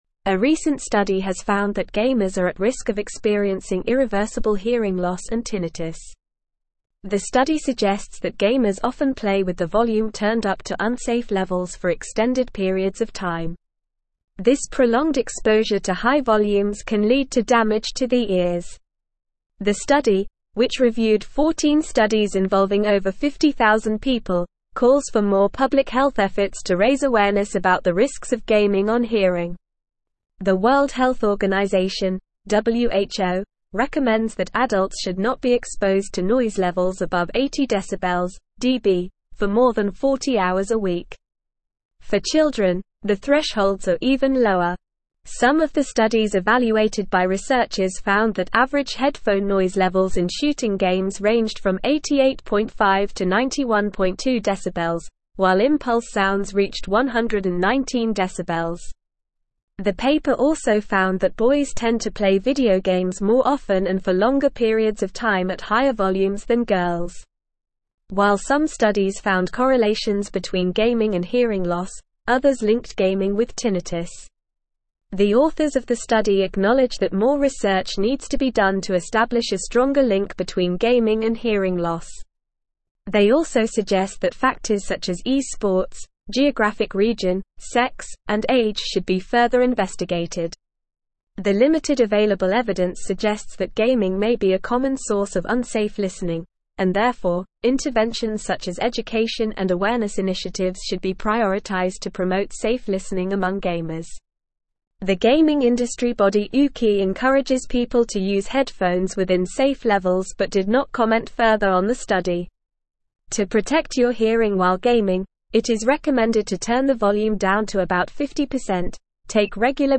Normal
English-Newsroom-Advanced-NORMAL-Reading-Gamers-at-Risk-of-Hearing-Loss-and-Tinnitus.mp3